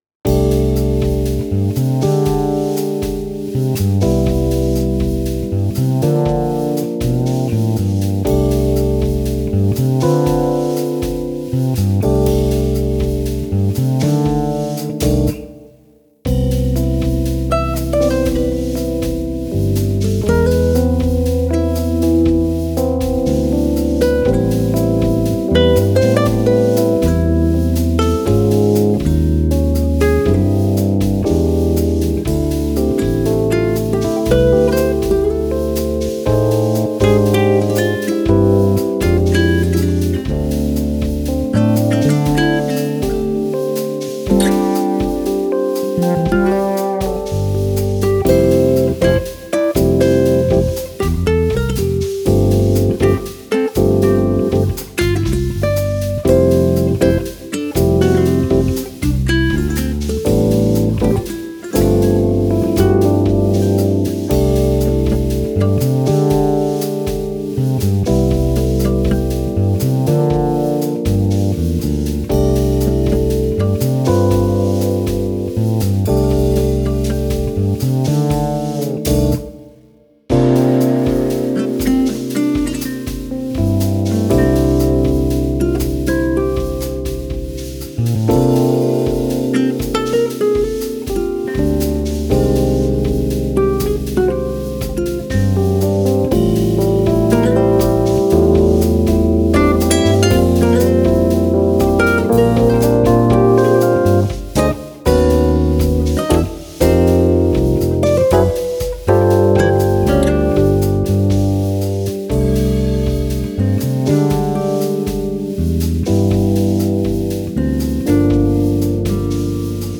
[Mixdown]Let's try to do something (jazzy)
Собственно говоря - очередная короткая пьеска.